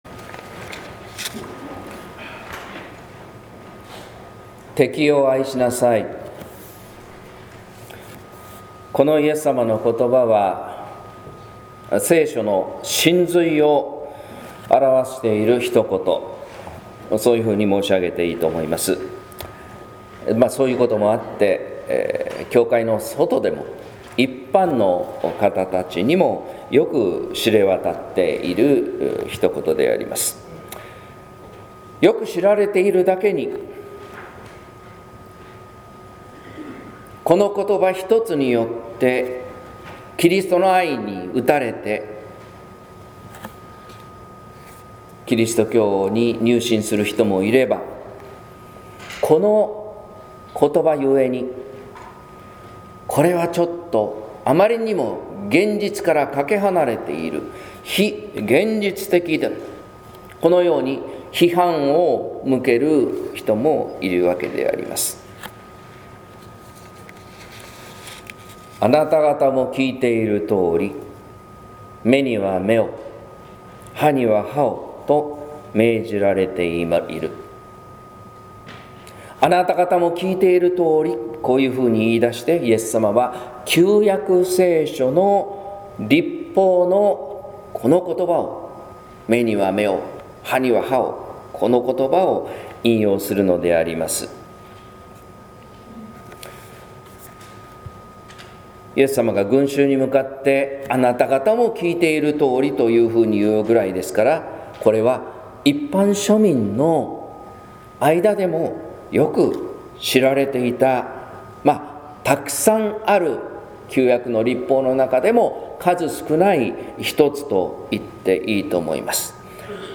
説教「関係づくりの原則」（音声版） | 日本福音ルーテル市ヶ谷教会